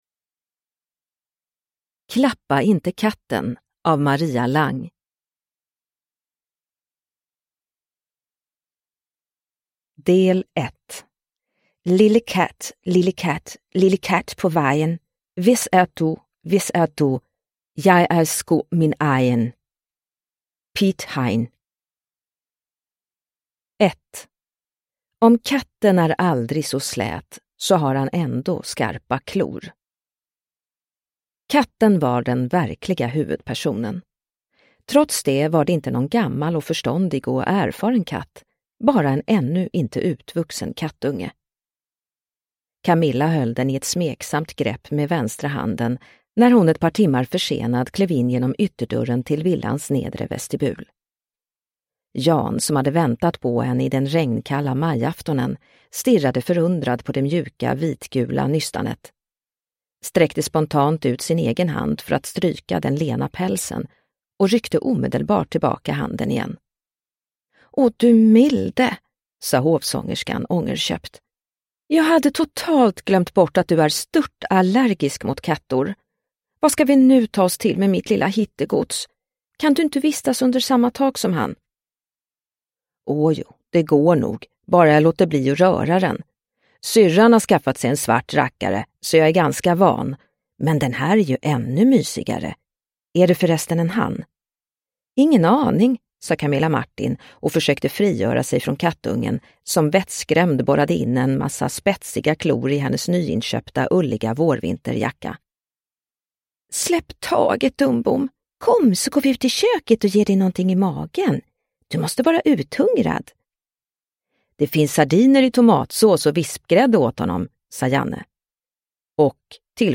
Klappa inte katten – Ljudbok – Laddas ner